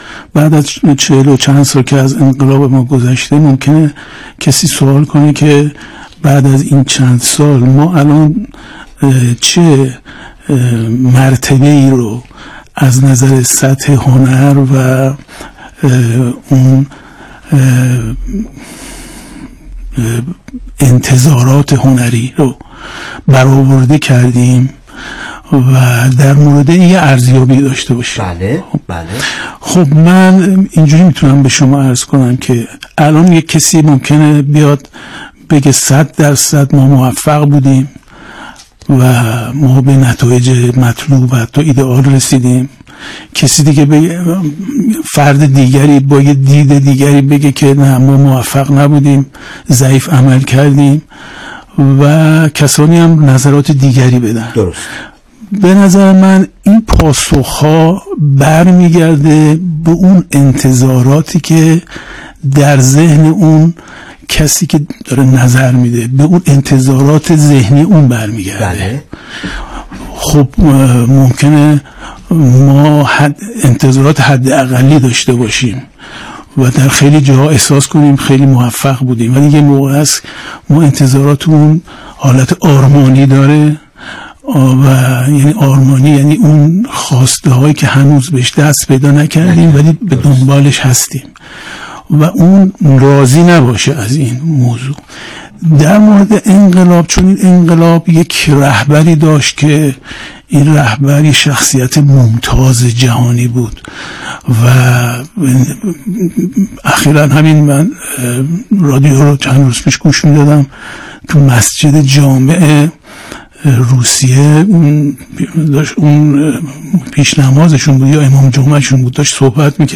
میزگردی تعاملی